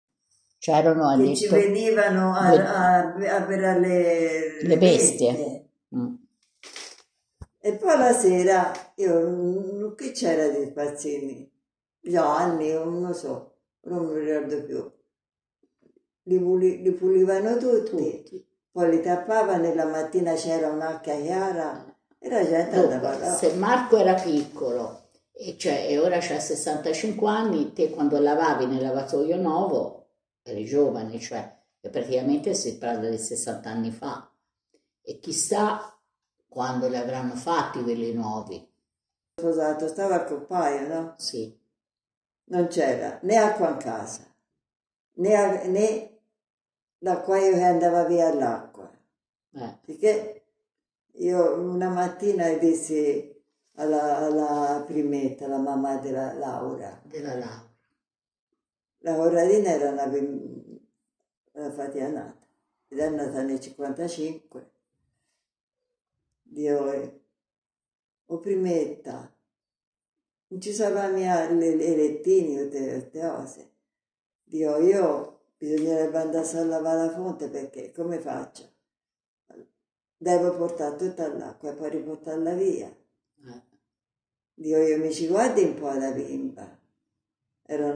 Testimonianza audio